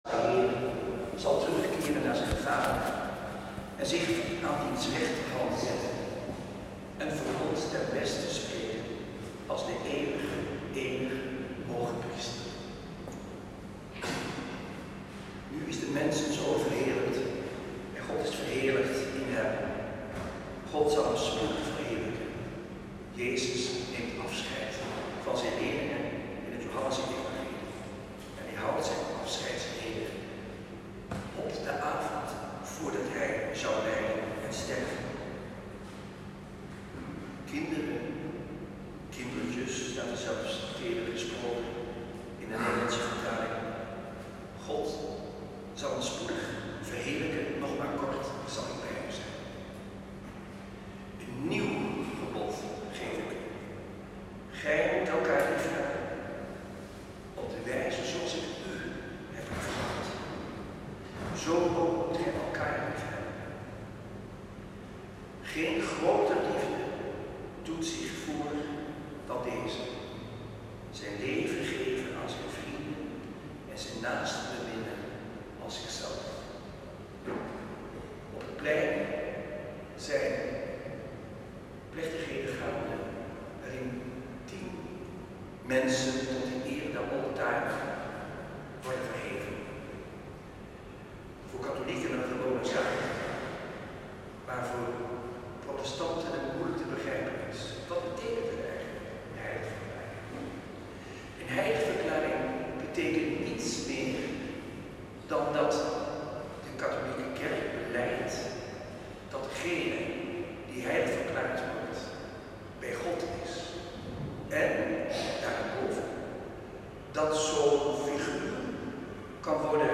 Vijfde zondag van Pasen. Celebrant Antoine Bodar.
Preek